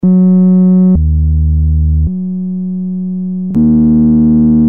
Suitcase monophonic bass transitors synthesizer made in Germany- compact keyboard based on 3 presets with no possible edit.
edit VOICE single oscillator structure based on MM74C14N which generates squared waveforms.
waveform <- All presets sound quite similar but the attack which is more sharped on guitar preset, tuba is more square-shapped sound with overtones.
Vintage design cheesy sound